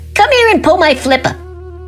• Get the idea (Best Hecklefish lines from the Why Files on Youtube)
Using this technique, we are incentivized to begin editing by simply beginning with shocking suggestions from a talking fish in the toilet at 2:30 AM, lol.